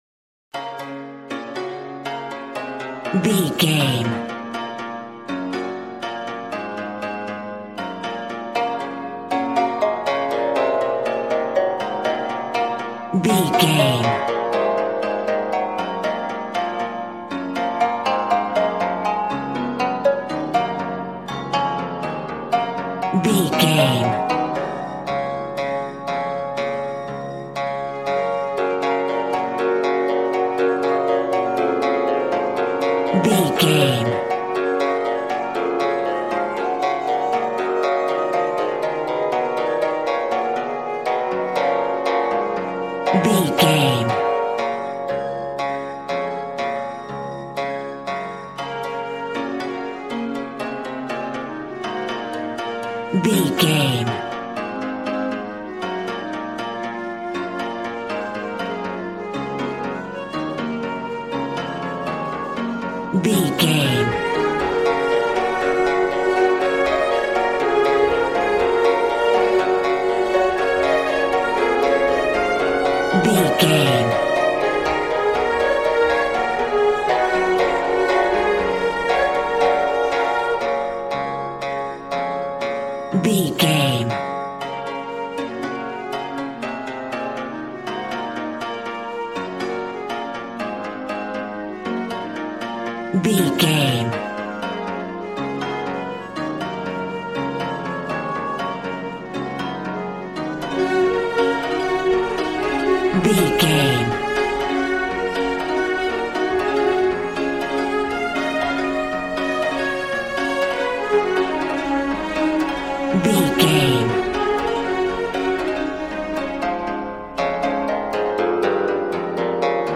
Aeolian/Minor
B♭
smooth
conga
drums